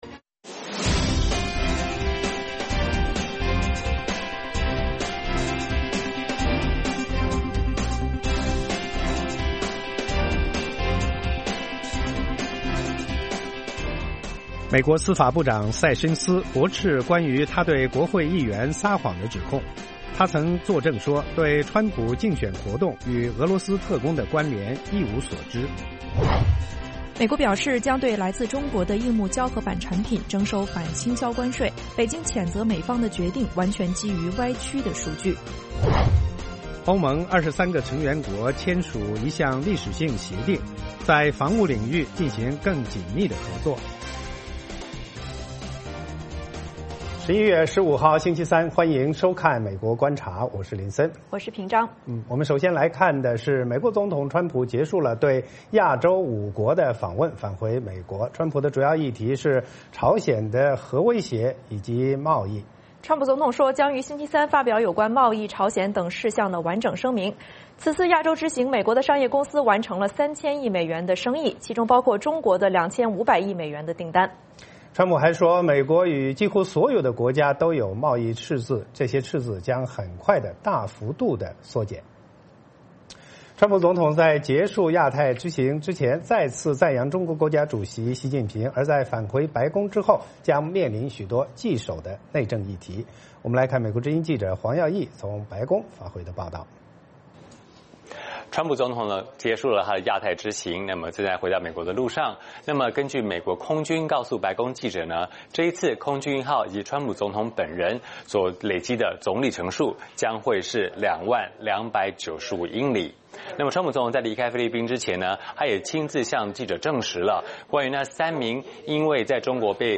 北京时间早上6-7点广播节目，电视、广播同步播出VOA卫视美国观察。 “VOA卫视 美国观察”掌握美国最重要的消息，深入解读美国选举，政治，经济，外交，人文，美中关系等全方位话题。节目邀请重量级嘉宾参与讨论。